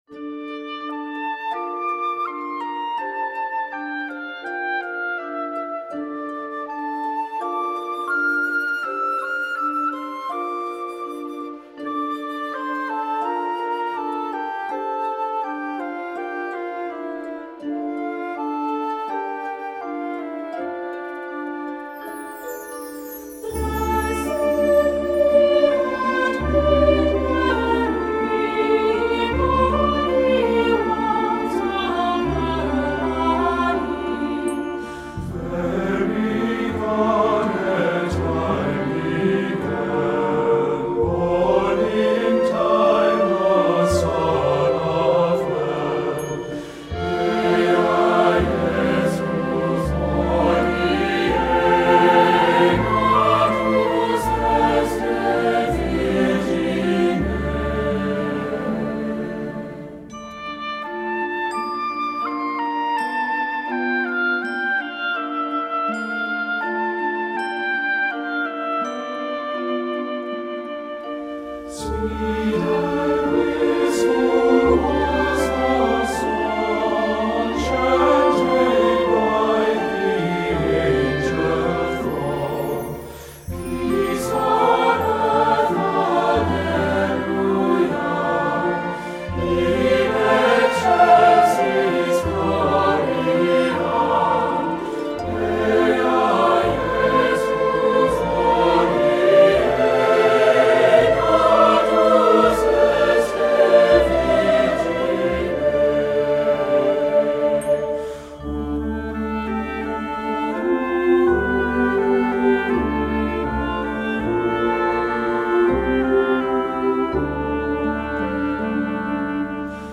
Singen, Chor, gemischter Chor, Kirchenchor, sakral, Fischingen,
Projekt für die Mitternachtsmesse 2024